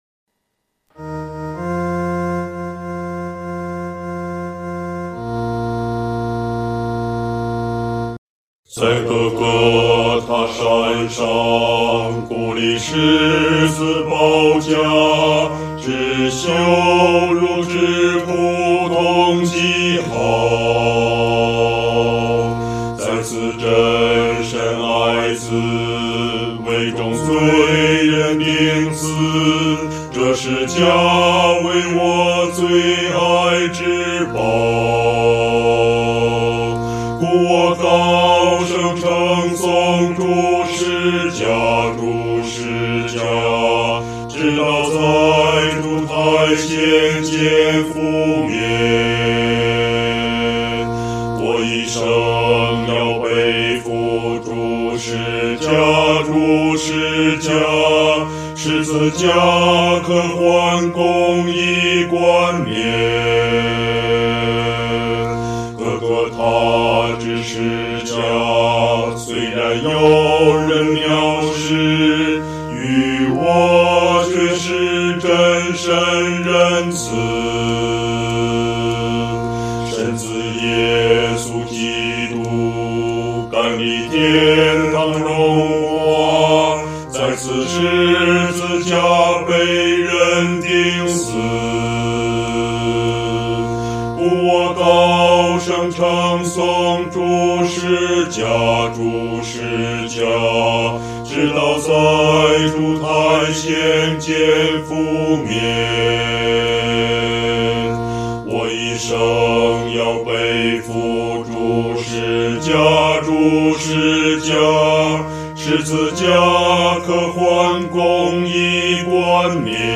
合唱
四声 下载
本首圣诗由网上圣诗班 (环球）录制